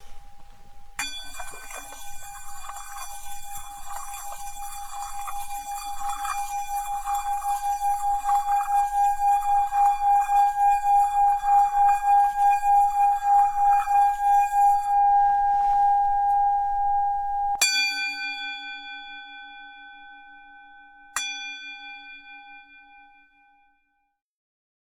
Bols tibétains
Le bol peut être effleuré sur tout son pourtour dans un geste circulaire (comme les verres en cristal mais avec une mailloche) ou frappé avec la mailloche.